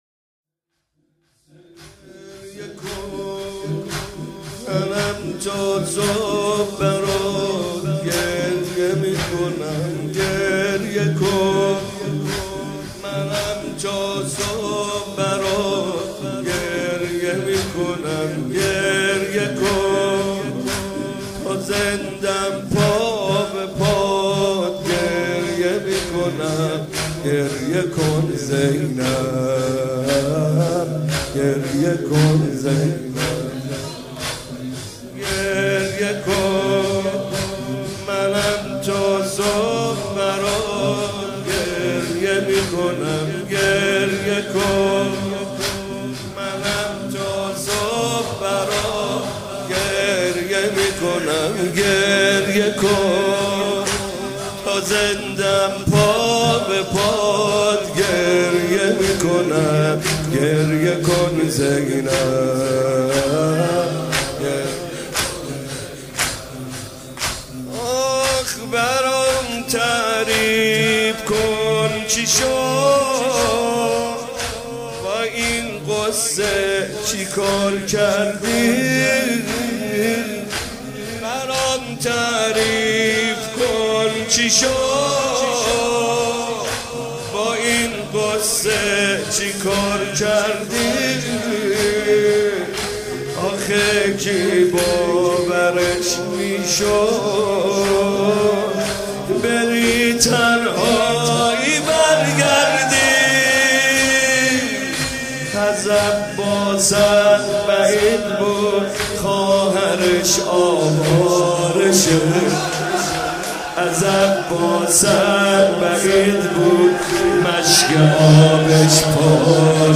گلچین مداحی های وفات حضرت ام البنین(س)